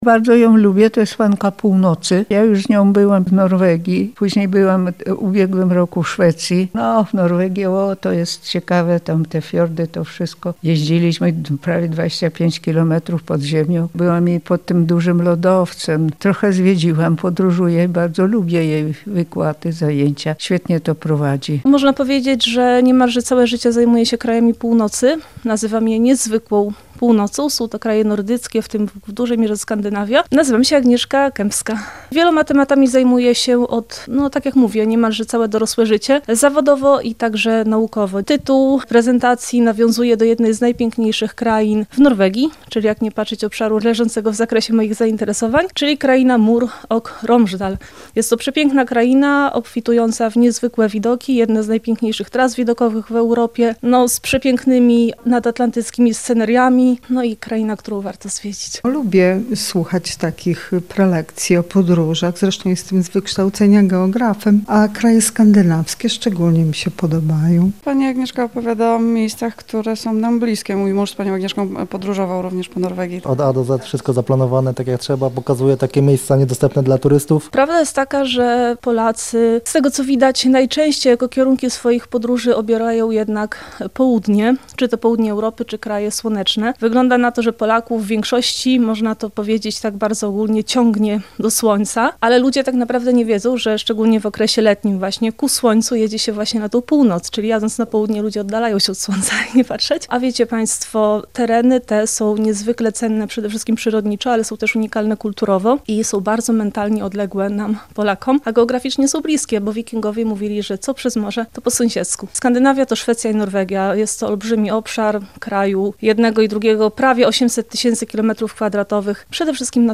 Opowieść o nieznanej Norwegii. Spotkanie z wyjątkową podróżniczką